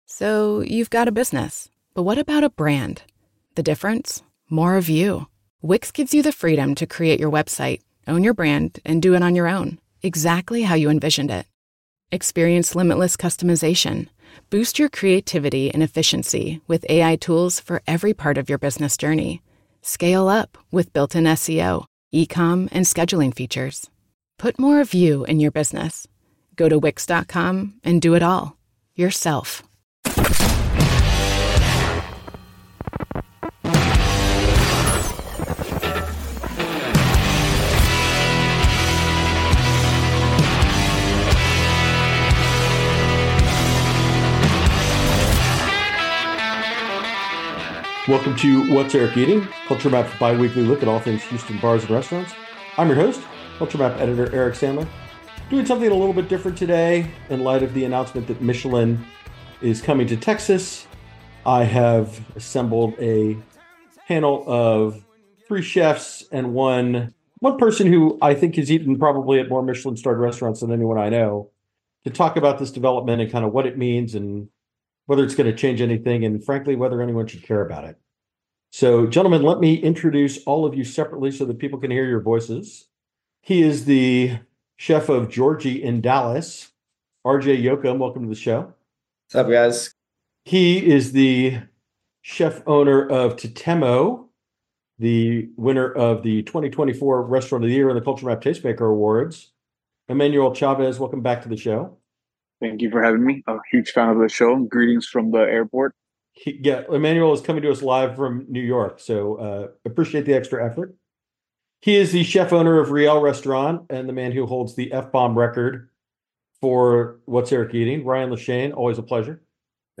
roundtable free flowing discussion